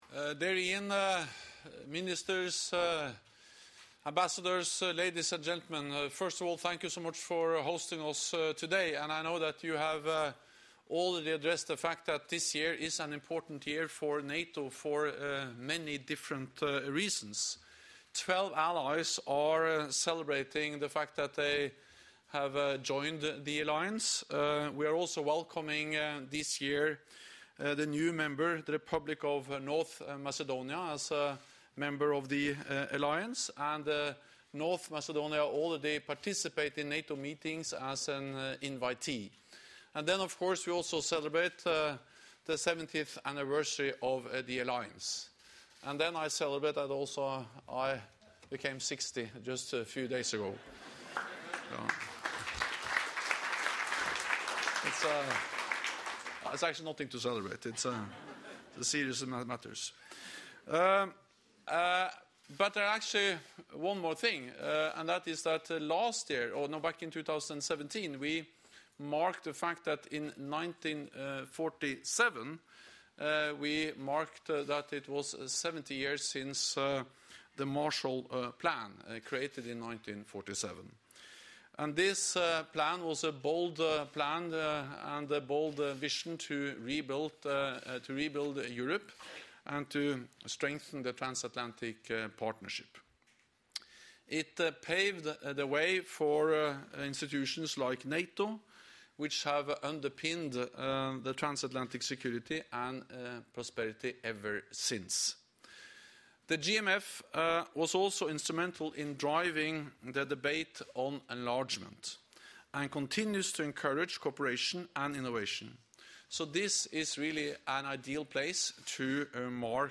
Keynote speech
by NATO Secretary General Jens Stoltenberg at the German Marshall Fund event: NATO at Seventy – Post-Cold War Enlargement and the Future of Transatlantic Security